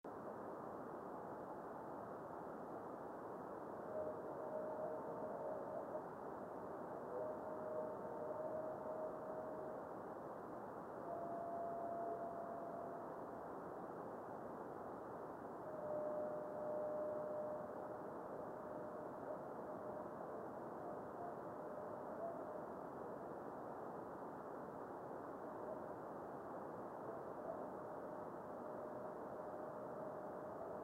Hard to see in movie but sonically evident though subtle.
Radio spectrogram of the time of the above meteor.  61.250 MHz reception above white line, 83.250 MHz below white line.